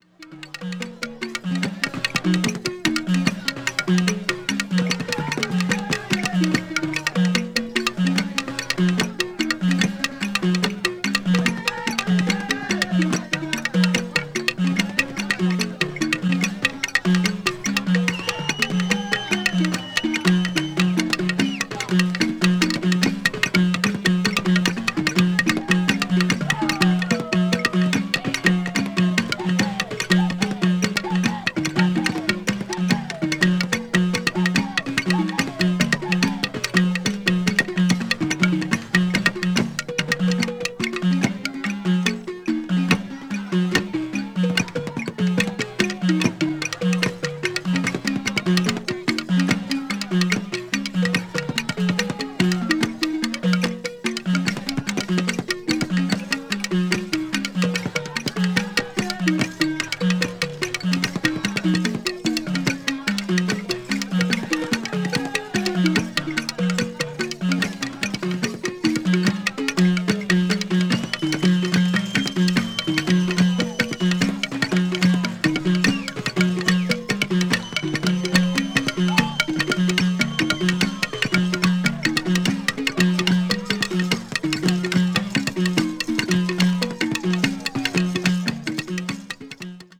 ethnic music   field recording   ghana   primitive   traditional   west africa